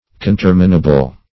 Search Result for " conterminable" : The Collaborative International Dictionary of English v.0.48: Conterminable \Con*ter"mi*na*ble\, a. Having the same bounds; terminating at the same time or place; conterminous.